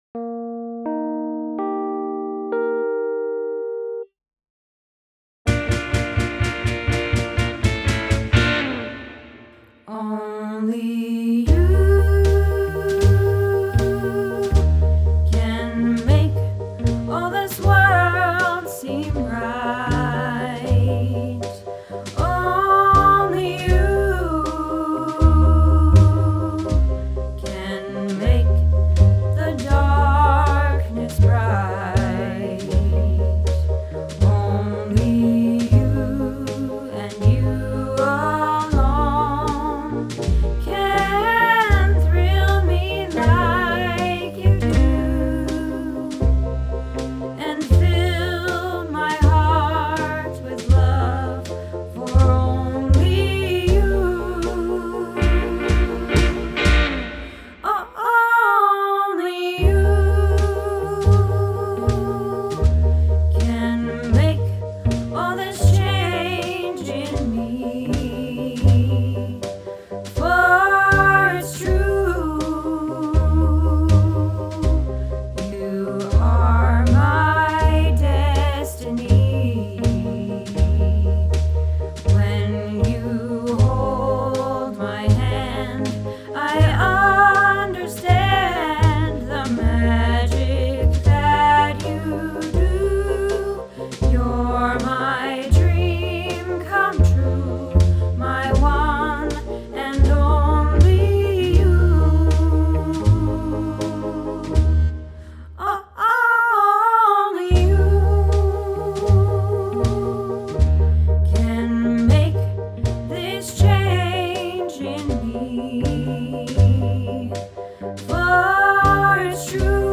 Only You - Alto